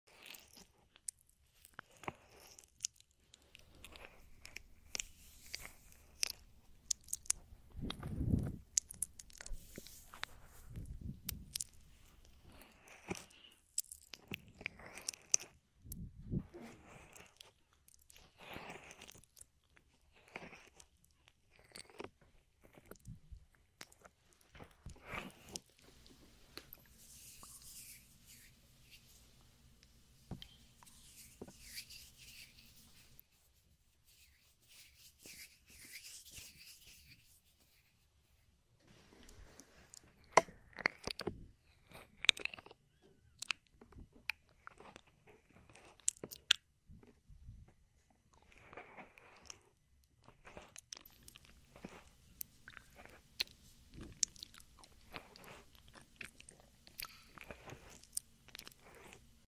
asmr Nom lick nibble ear sound effects free download
asmr Nom lick nibble ear eating sound👅